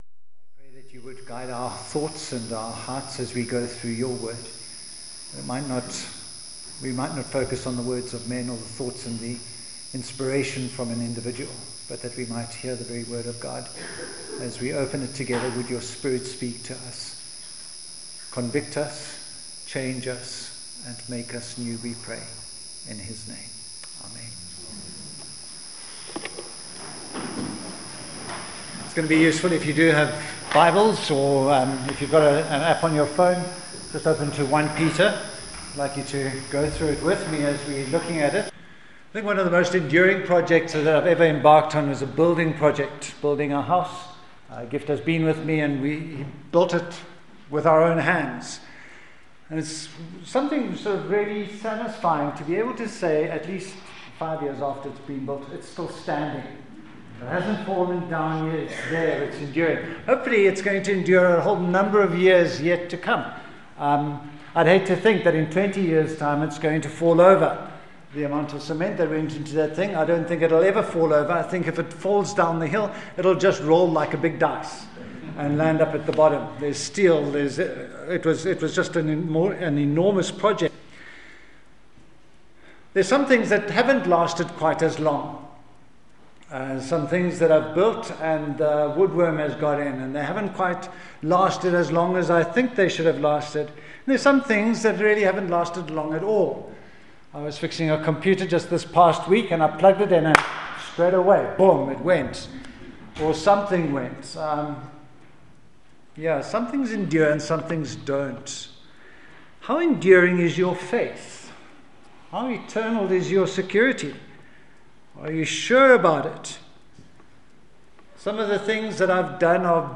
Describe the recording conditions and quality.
1Peter 1:17-25 Service Type: Sunday Morning The Problem The Price The Protection The Path « Colossians